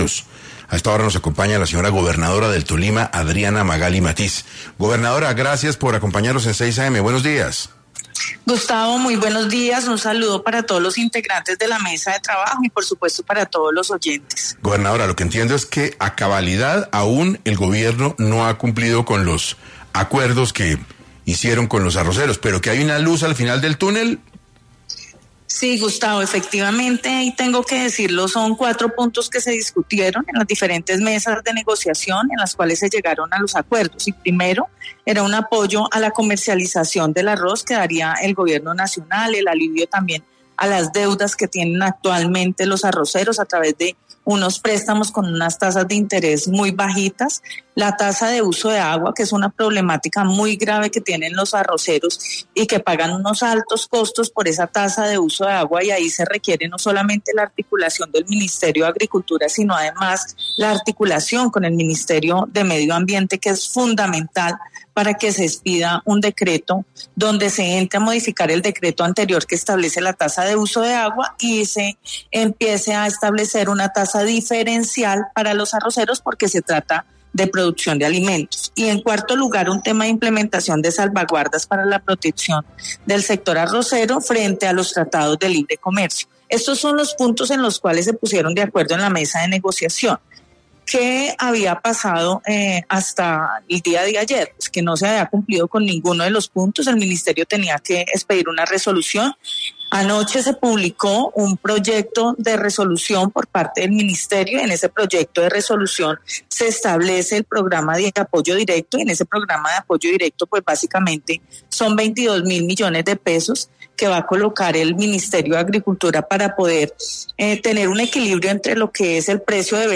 En 6AM de Caracol Radio habló Adriana Magali Matiz, gobernadora del Tolima quien habló sobre el incumpliendo de los acuerdos con los arroceros por parte del Gobierno